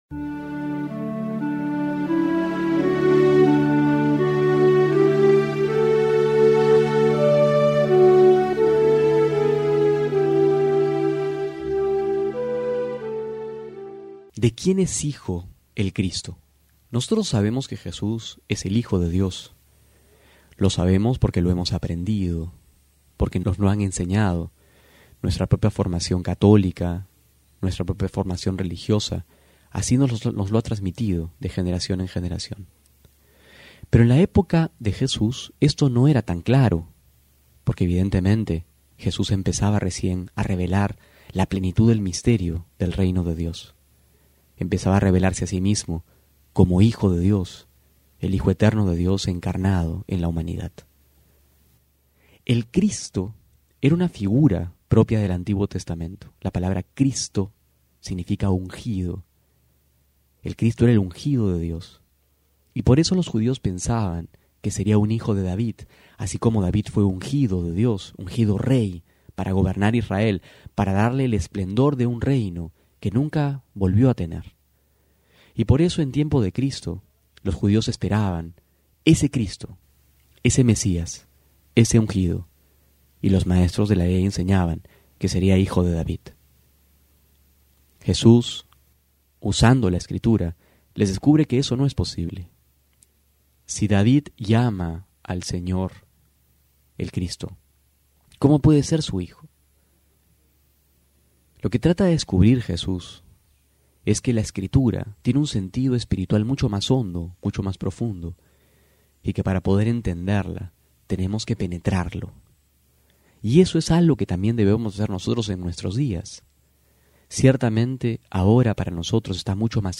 Homilía para hoy:
junio08-12homilia.mp3